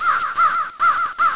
Crow Caw